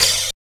88 FLNG OPEN.wav